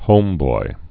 (hōmboi)